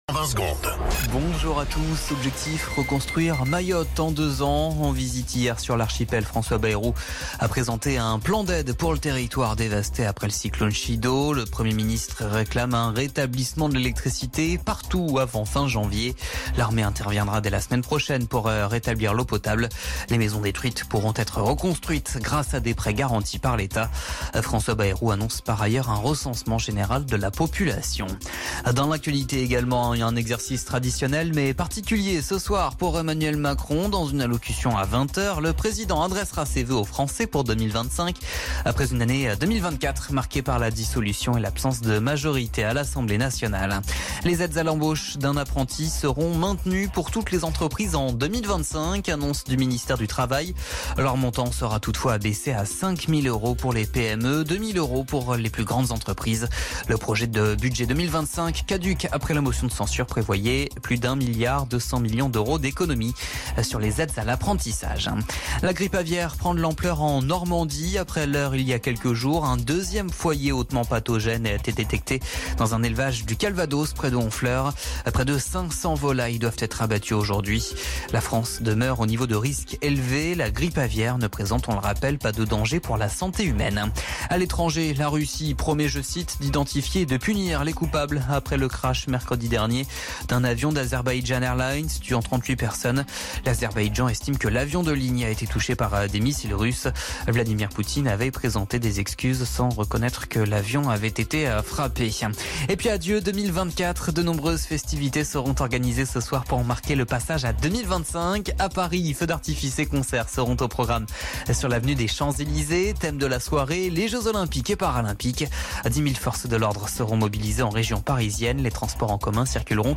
Flash Info National 31 Décembre 2024 Du 31/12/2024 à 07h10 .